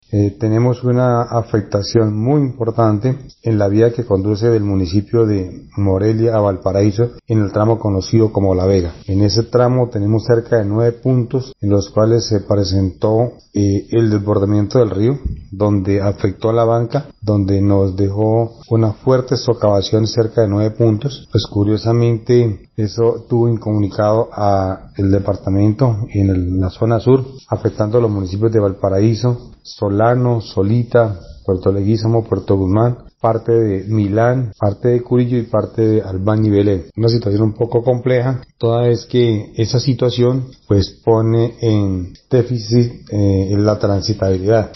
Así lo dio a conocer el alcalde del municipio de Valparaíso, Arbenz Pérez, al mencionar que solicitó la ayuda del gobierno departamental con maquinaría amarilla que permita restablecer el tránsito por el lugar afectado, donde se debe realizar trasbordos por lo complejo de la situación.
ALCALDE_ARBENZ_PEREZ_CARRETERA_-_copia.mp3